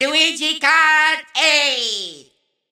Voice clip from Mario Kart 8